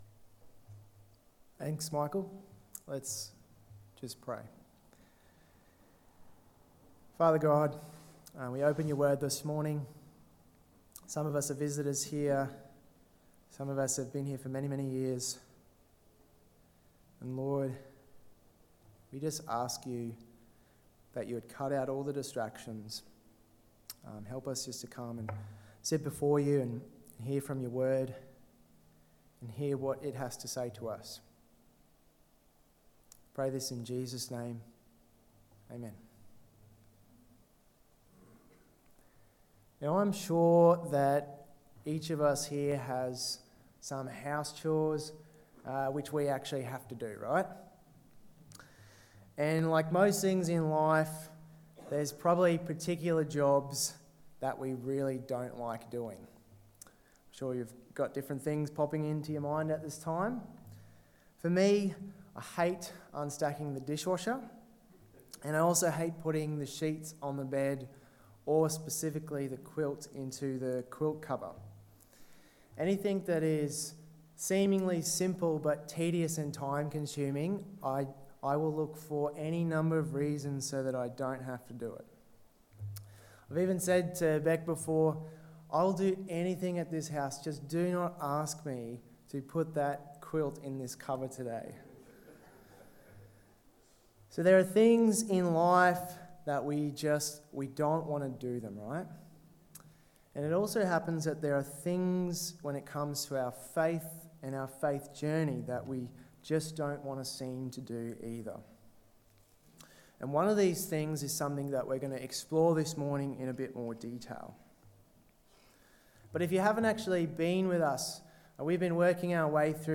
The Burning Bush Pt.3: A Reluctant Redeemer (Exodus 4:1-20 Sermon) 11/06/2023